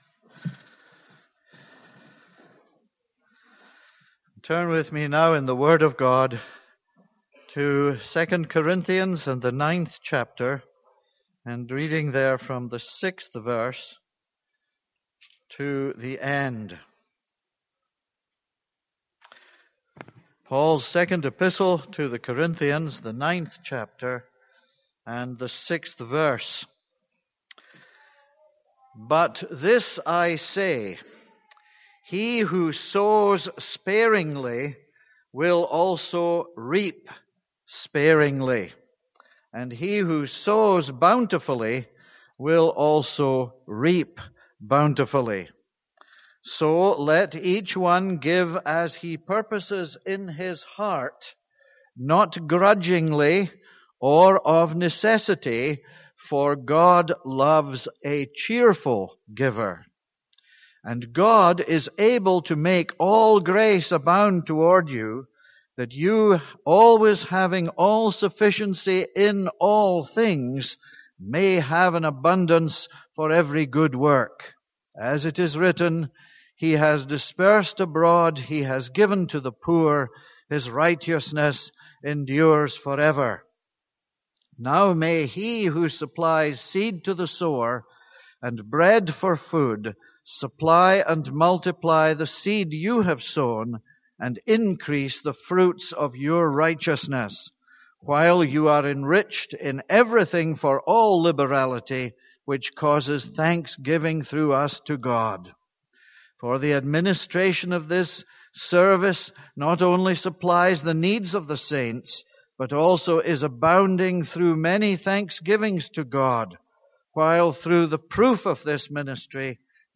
This is a sermon on 2 Corinthians 9:6-15.